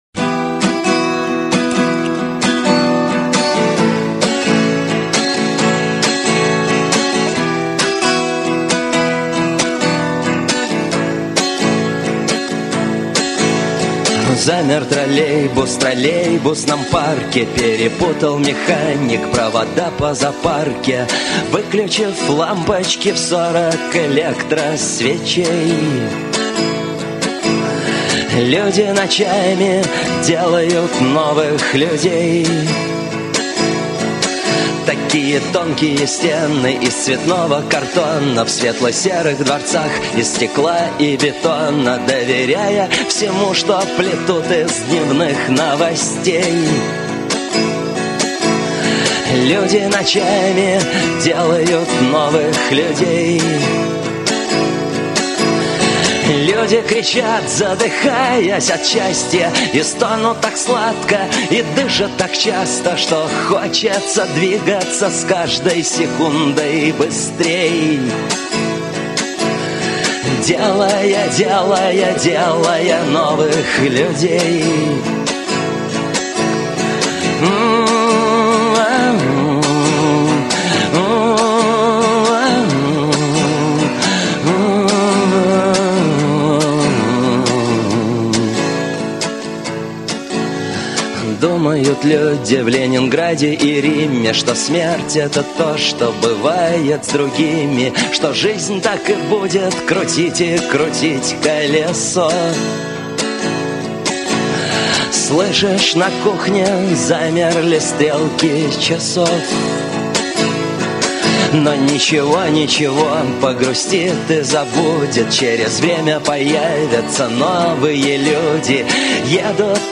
(акустика)